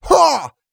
XS普通4.wav 0:00.00 0:00.73 XS普通4.wav WAV · 63 KB · 單聲道 (1ch) 下载文件 本站所有音效均采用 CC0 授权 ，可免费用于商业与个人项目，无需署名。
人声采集素材